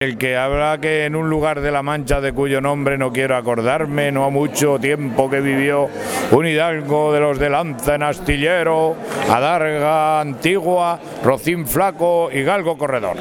La lectura arrancó en español con el inolvidable “En un lugar de La Mancha...”, a partir del cual se fueron intercalando los distintos lectores -todos ellos en braille- que nos trasladaron el texto en noruego, inglés, polaco, rumano, francés, italiano, portugués, ruso, japonés, esperanto, árabe, chino, alemán e incluso en asturiano, entre otros.